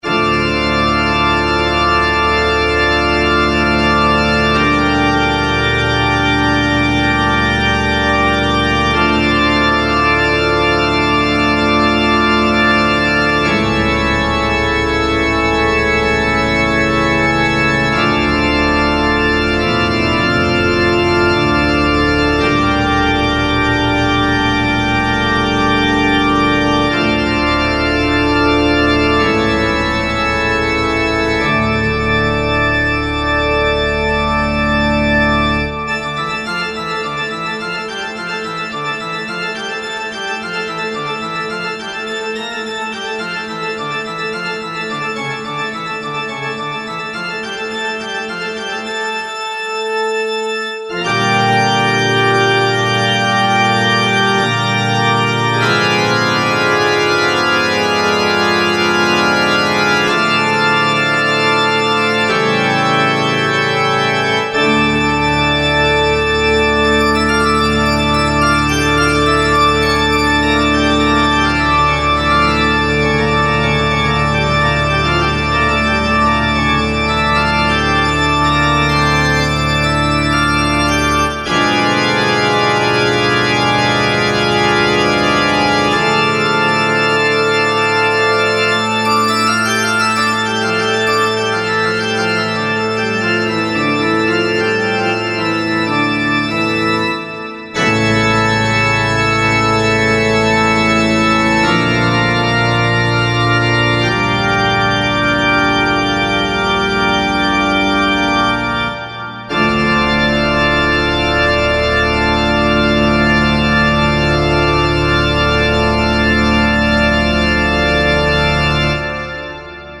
guitare - harpe - aerien - folk - melodieux